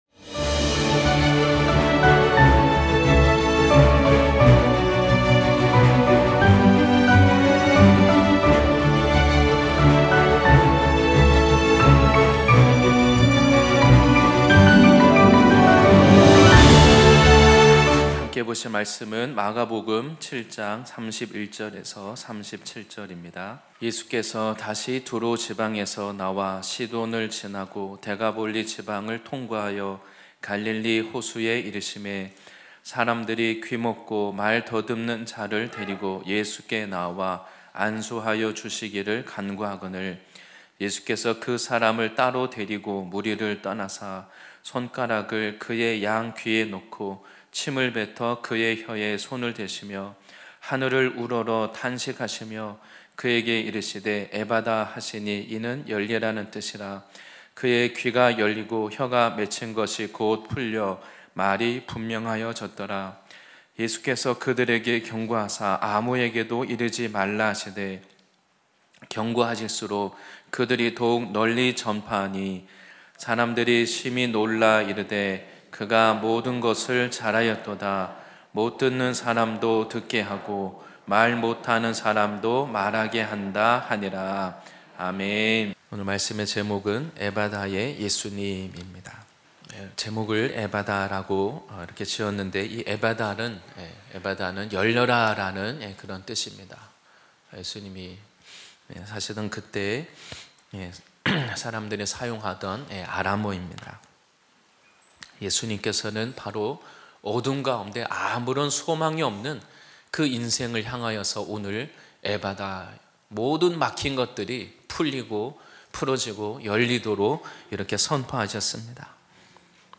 주일예배말씀